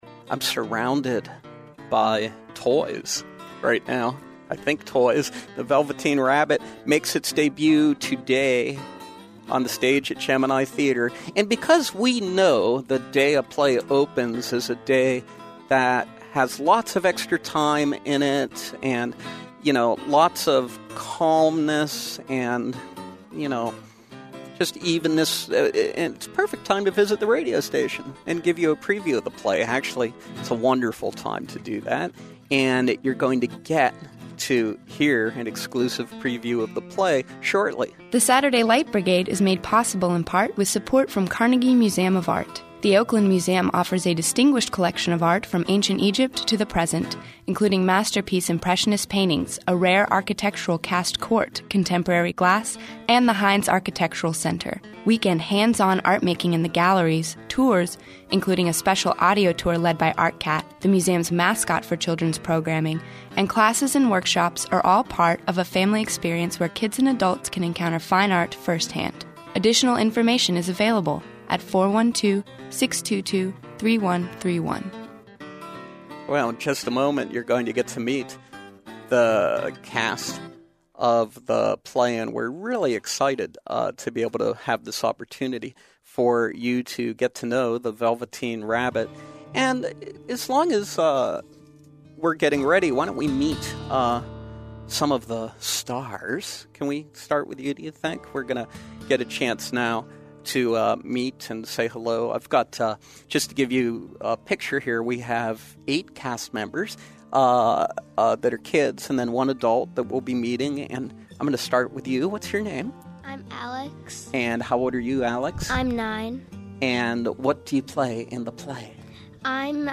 The Gemini Theater produces original, interactive, children’s musicals which focus on artistic, cultural and educational themes. This week we welcome members of the cast of Velveteen Rabbit as they preview their production of this classic story.